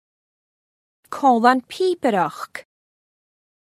Amazon AWS (pronunciation).